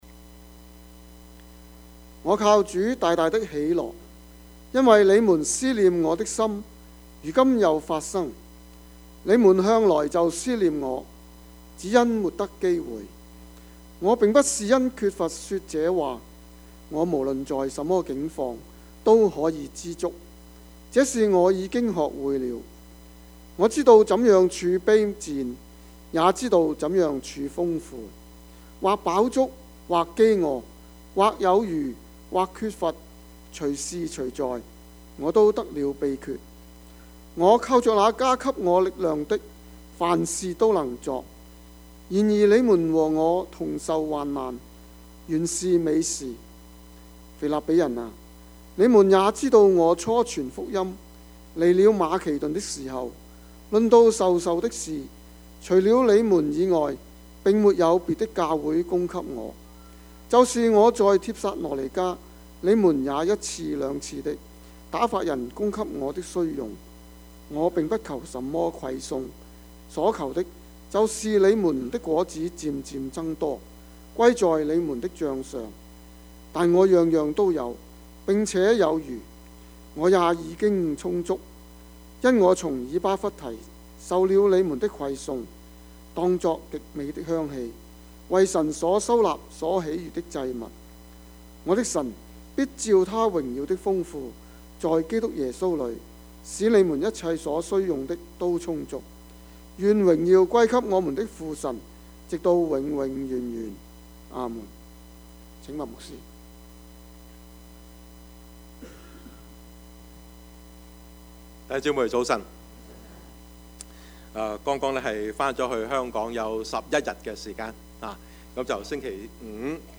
Topics: 主日證道 « 應當一無掛慮 十字架與喜樂 »